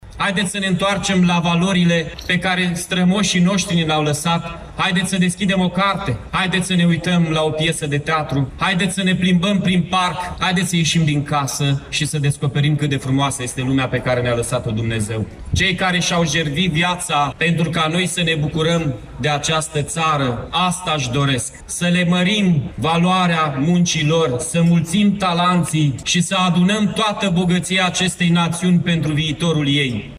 La Iași manifestările care marchează Ziua Națională a României s-au desfășurat în Piața Palatului din municipiu, iar acțiunile au continuat cu un spectacol de muzică populară.
În discursul său, primarul Mihai Chirica a subliniat importanța reflecției și a întoarcerii la valorile care caracterizează poporul român: ”Haideți să ne întoarcem la valorile pe care strămoșii noștri ni le-au lăsat, haideți să deschidem o carte, haideți să ne uităm la o piesă de teatru, haideți să ne plimbăm prin parc, haideți să ieșim din casă și să descoperim cât de frumoasă este lumea pe care ne-a lăsat-o Dumnezeu. Cei care și-au jerfit viața pentru ca noi să ne bucurăm de această țară, asta își doresc, să le mărim valoarea muncilor, să mulțim talanții și să adunăm toată bogăția acestei națiuni pentru viitorul ei.”
1-dec-ora-13-Mihai-Chirica.mp3